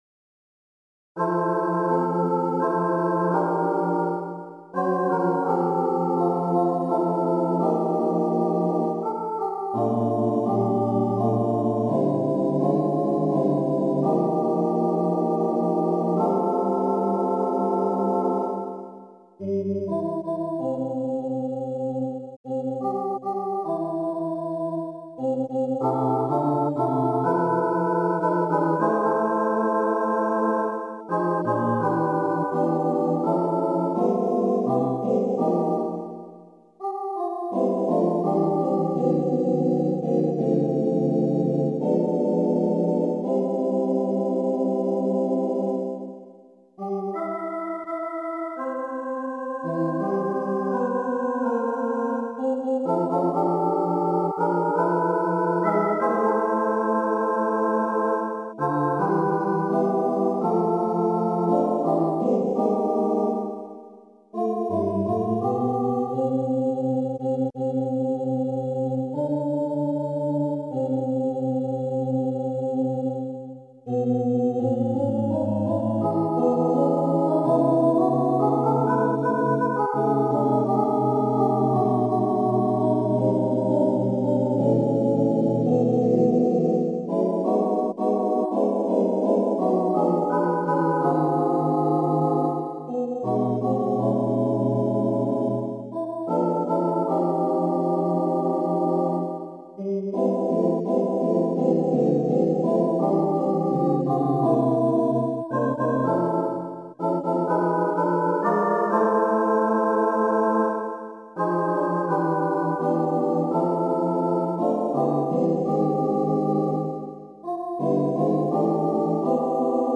なんとか昔の忘れ物を取りに行きたくてコピーしてみた。